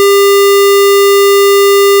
square wave:
sound-square.wav